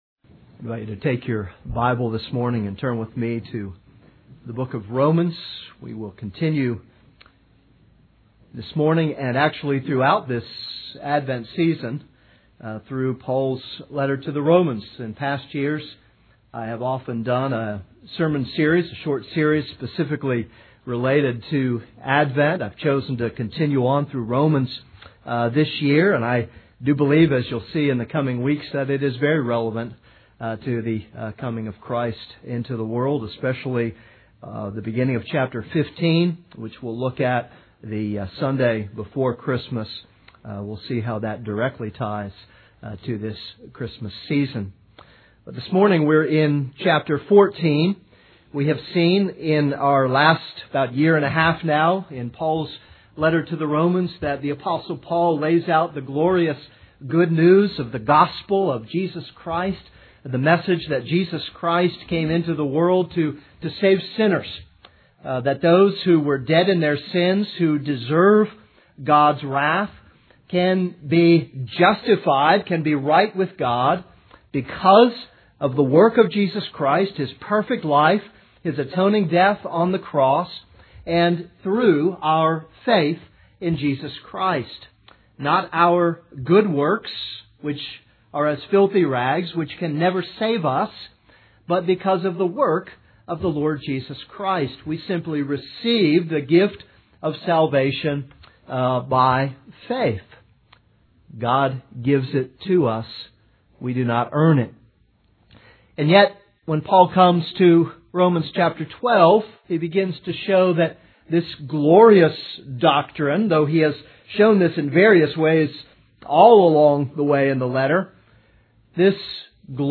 This is a sermon on Romans 14:1-12.